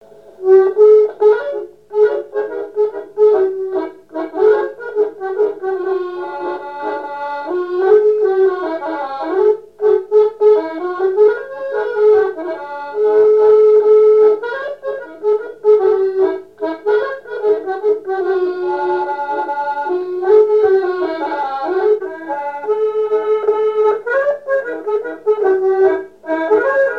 danse : marche
Genre strophique
Pièce musicale inédite